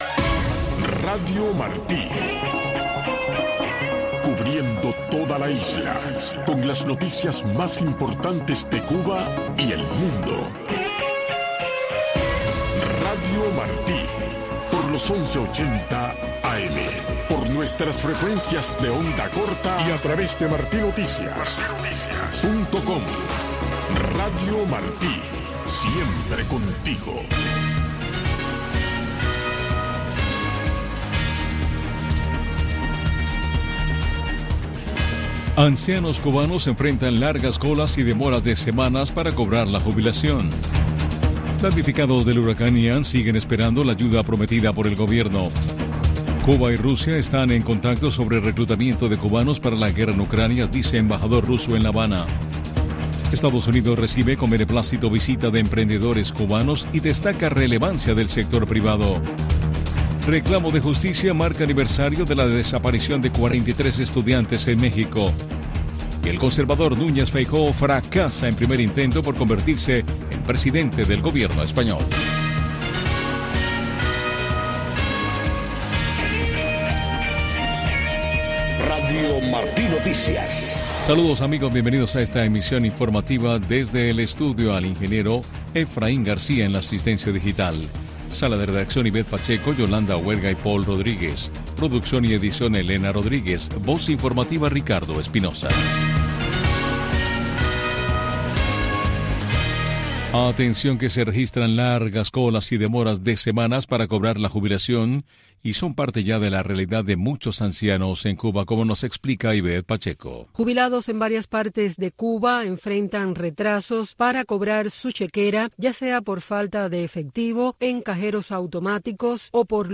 Noticiero de Radio Martí 3:00 PM